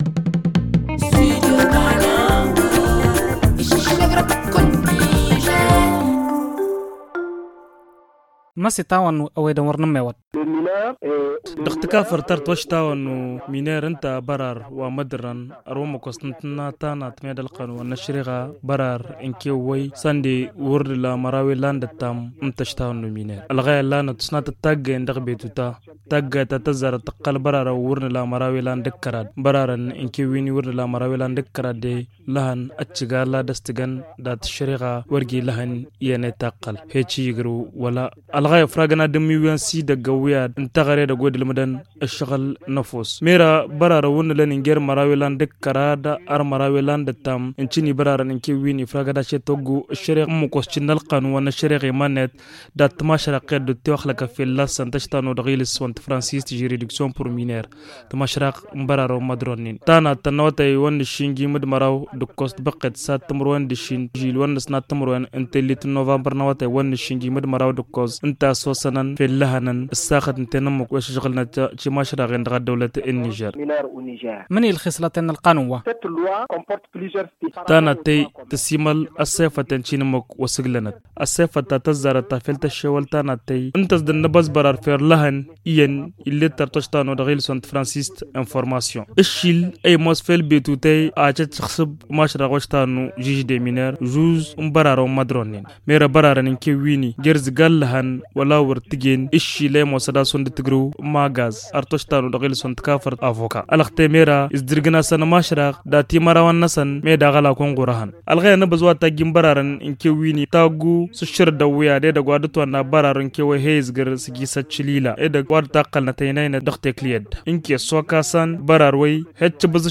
Mamoudou Idrissa, président du tribunal de Tchintabaraden explique.